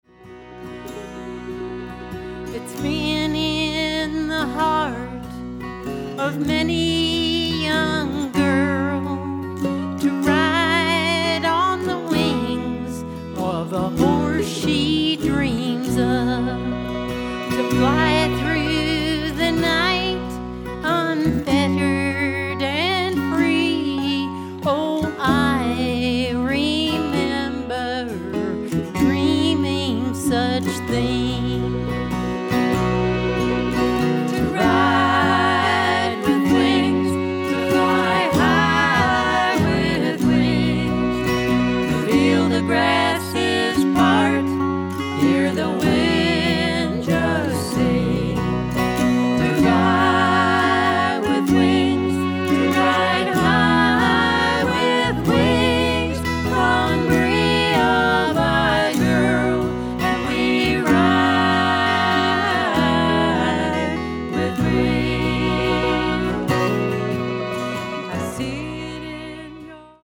this time with all three women singing leads and harmonies.
A great folk-country sound.